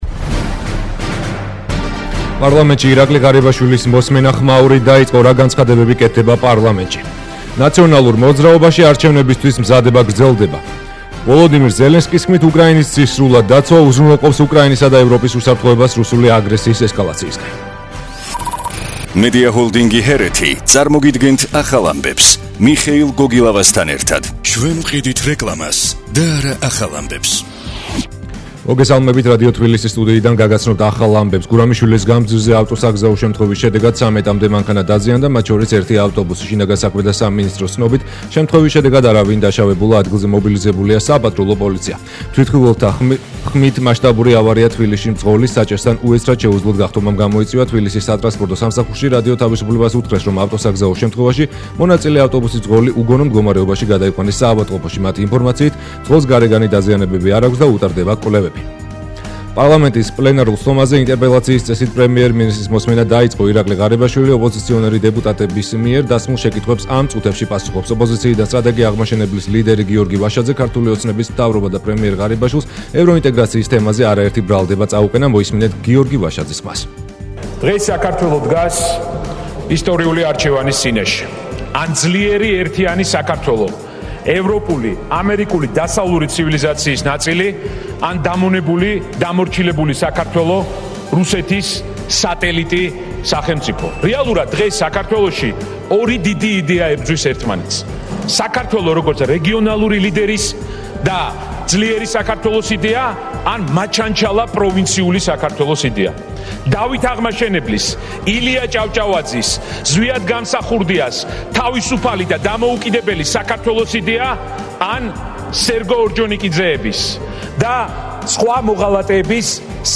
ახალი ამბები 13:00 საათზე - HeretiFM